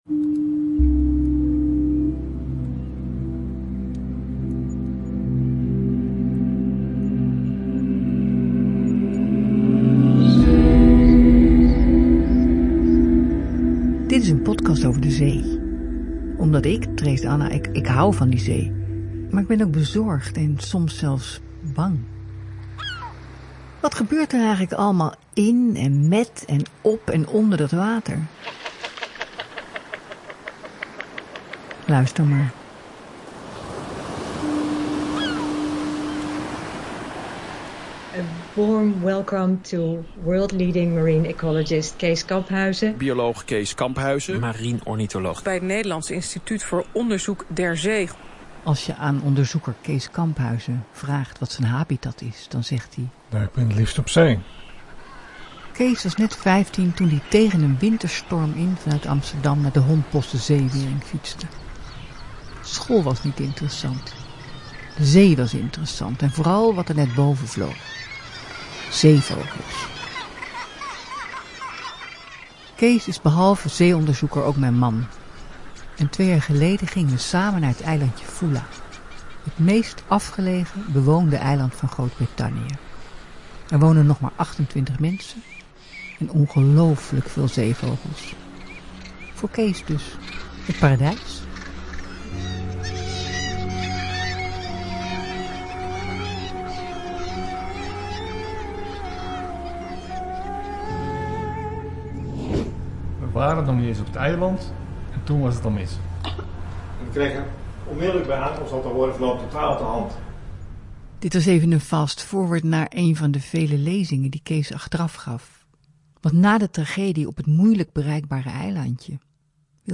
Beide opnames zijn gemaakt tijdens de vogelgriep uitbraak op het eiland.